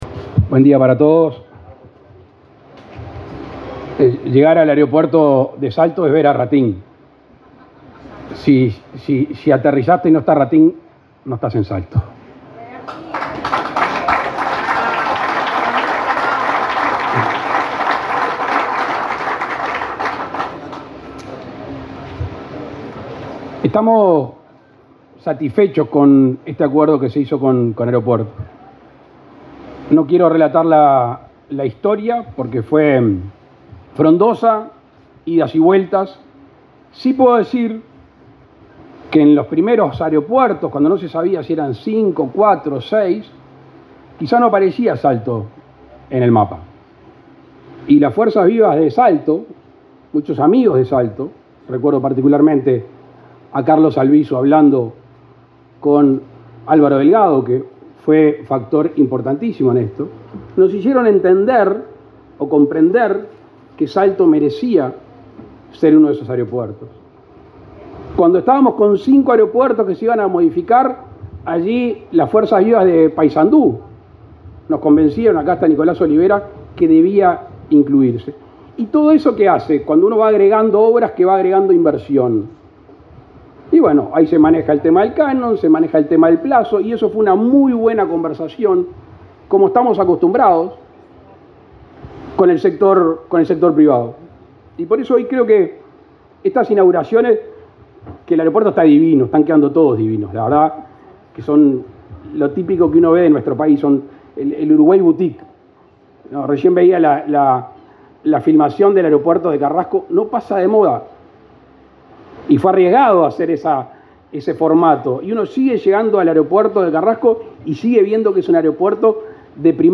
Palabras del presidente Luis Lacalle Pou
Este viernes 23, el presidente de la República, Luis Lacalle Pou, participó en la inauguración del aeropuerto internacional de Salto.